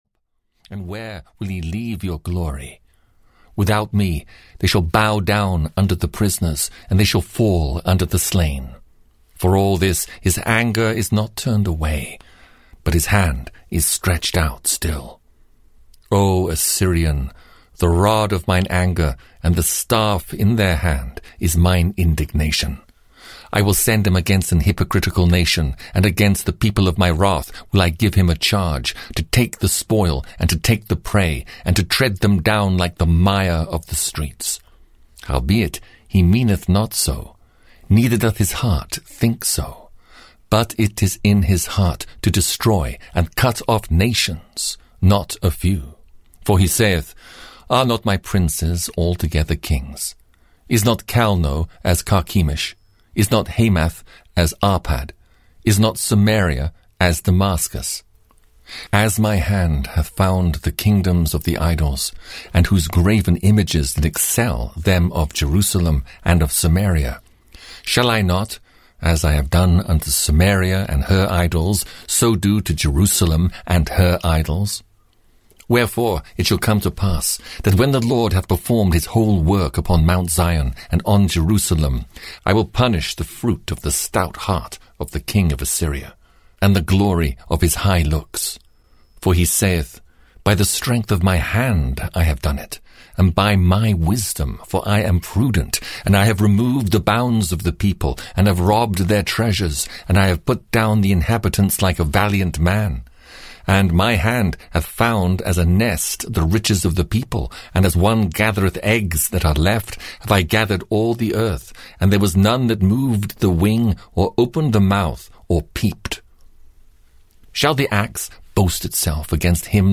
Audio knihaThe Old Testament 23 - Isaiah (EN)
Ukázka z knihy